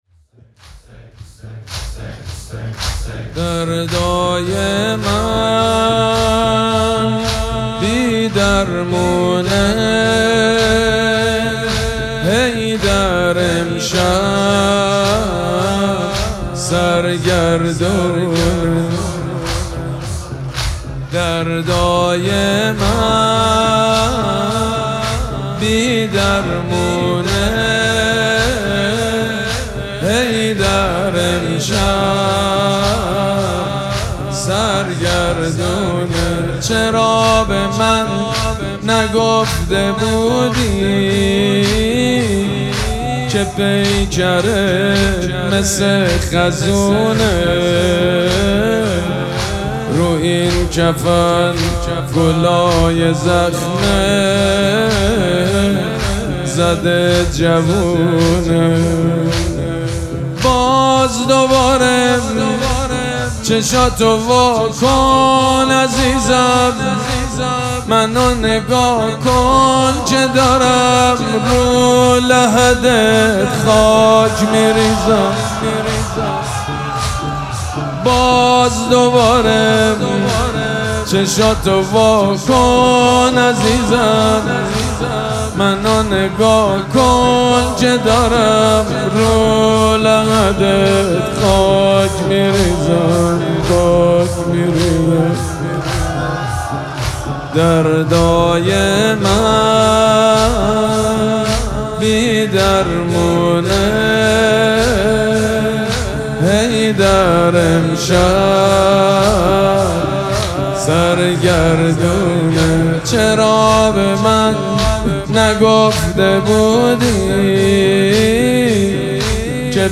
شب پنجم مراسم عزاداری دهه دوم فاطمیه ۱۴۴۶
حسینیه ریحانه الحسین سلام الله علیها
مداح
حاج سید مجید بنی فاطمه